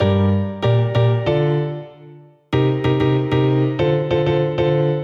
描述：添加了一些混响的钢琴循环。和弦是： g小调；d小调，c小调7；d小调7
Tag: 95 bpm Hip Hop Loops Piano Loops 870.97 KB wav Key : G